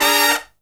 FALL HIT12-L.wav